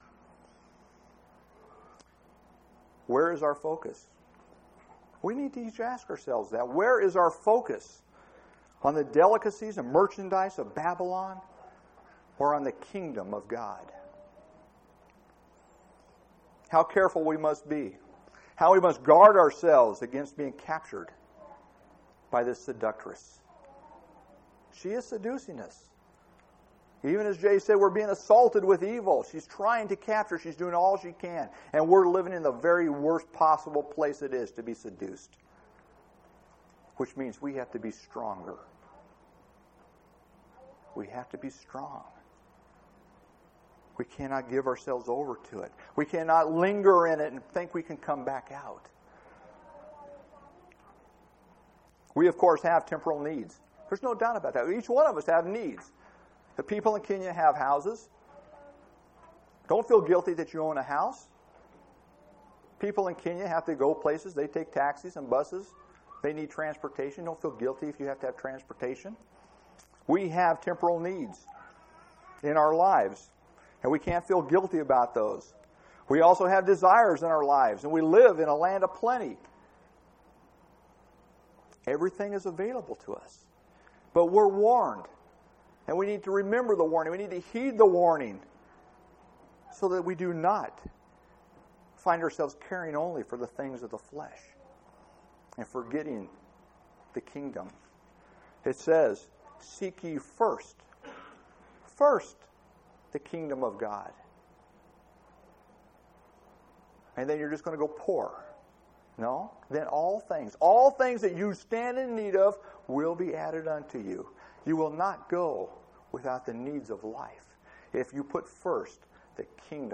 11/26/2000 Location: Phoenix Reunion Event